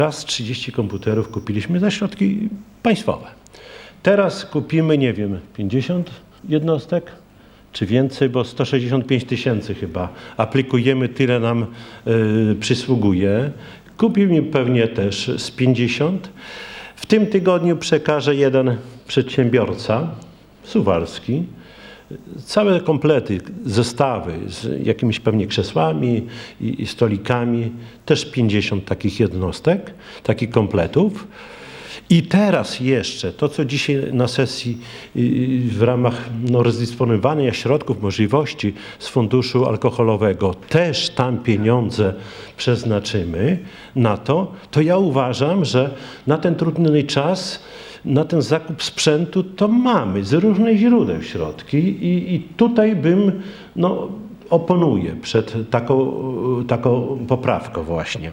Rada odrzuciła natomiast wniosek o przekazanie 100 tysięcy złotych na zakup komputerów do zdalnego nauczania. Czesław Renkiewicz, prezydent miasta zapewniał, że na ten cel miasto zabezpieczyło już pieniądze.